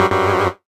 HeavenStudioPlus/Assets/Resources/Sfx/games/quizShow/incorrect.ogg at 7f5cae9cf73599a6e6e562292e9713b1a9ce91a9
incorrect.ogg